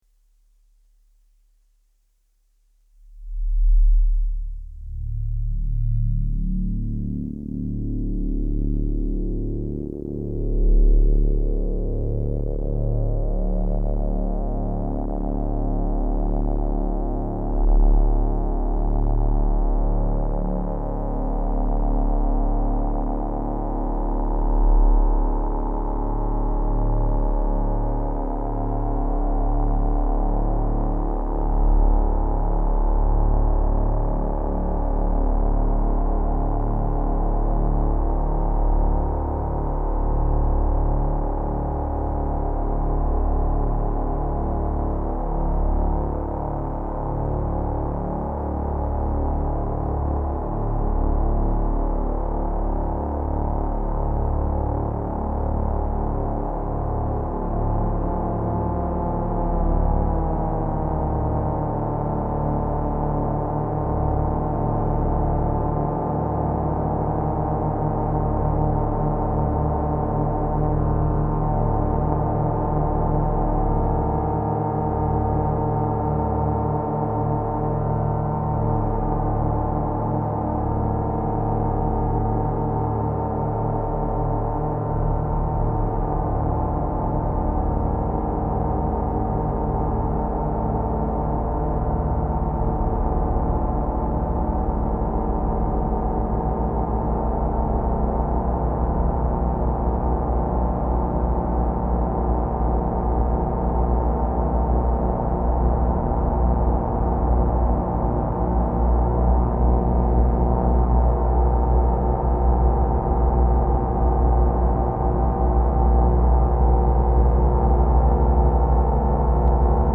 I also used long tones instead of more discrete sonic events, so the delay effect is more subtle, more smeared out in time. My Behringer ARP 2600 synthesizer clone seemed a good choice for this approach, hence the "ARP" in the name.
ARPdelay (mp3 format; 7:44 -- 11.2 Mbytes) This is another 'long delay' piece, following-on from delchords .